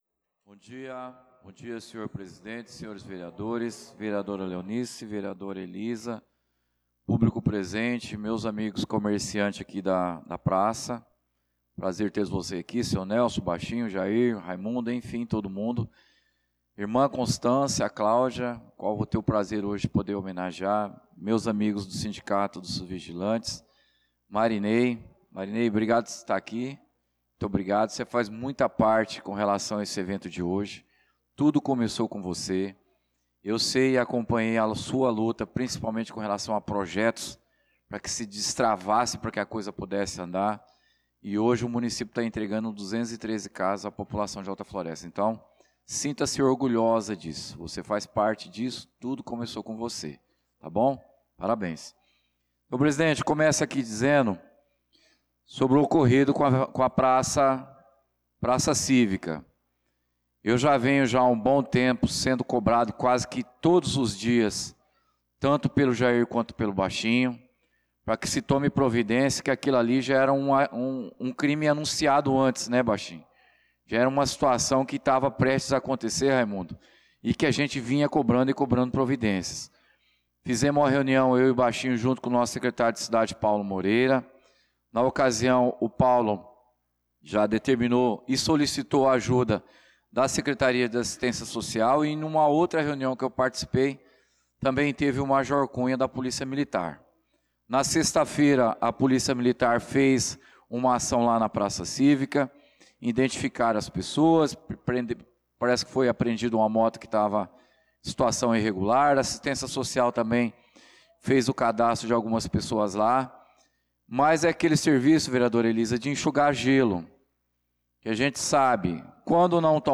Pronunciamento do vereador Claudinei de Jesus na Sessão Ordinária do dia 02/06/2025